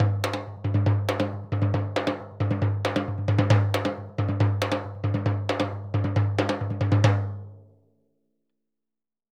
Surdo Merengue 136-2.wav